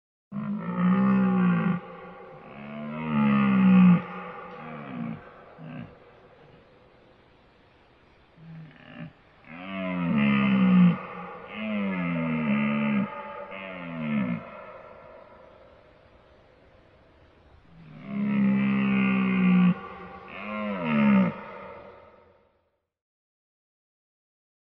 На этой странице собраны натуральные звуки оленей: от нежного фырканья до мощного рева в брачный период.
Ревет оленя